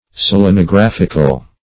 Search Result for " selenographical" : The Collaborative International Dictionary of English v.0.48: Selenographic \Sel`e*no*graph"ic\, Selenographical \Sel`e*no*graph"i*cal\, a. [Cf. F. s['e]l['e]nographique.]